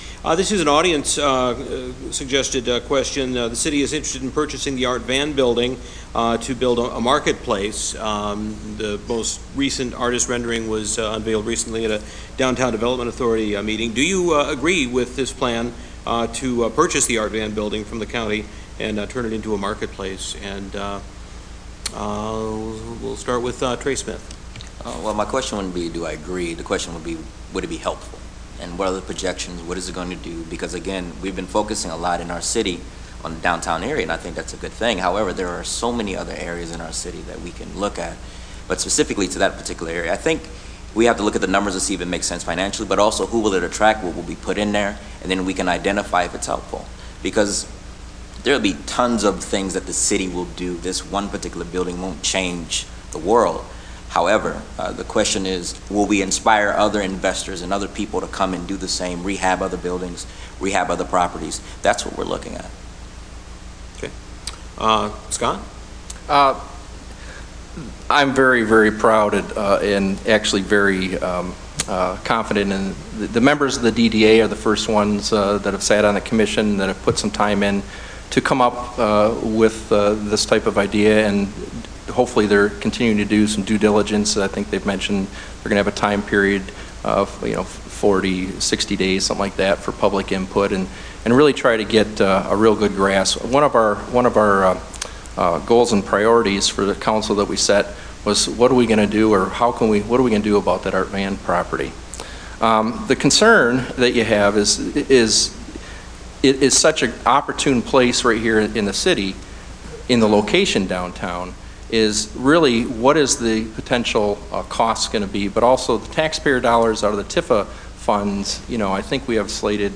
Council, mayoral candidates sound off during forum
Those hoping to be Port Huron’s next city leaders participated in a forum hosted by the Blue Water Area Chamber of Commerce and WPHM Radio Tuesday evening. All eight candidates for city council and all four mayoral candidates were in attendance. The city manager, parking, and unfunded liabilities were all brought up by Port Huron City Council hopefuls last night during the first half of Tuesday’s event.